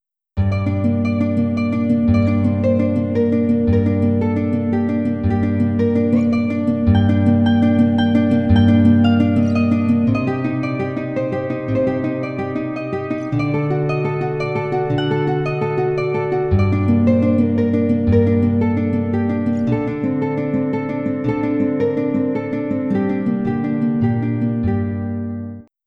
着信メロディ
尚、着メロの雰囲気を醸すために原曲のピッチを2度近く上げており、長さは30秒程度としています。